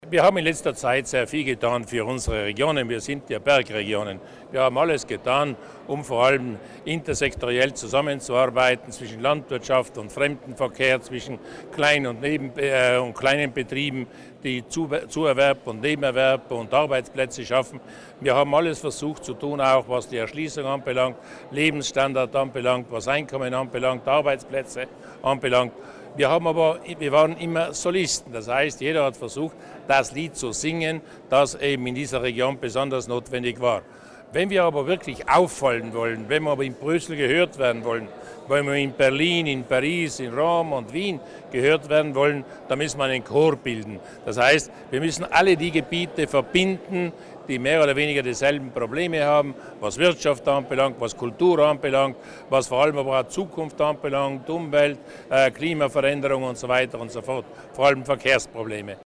Landeshauptmann Durnwalder zur Bedeutung einer gemeinsamen Strategie im Alpenraum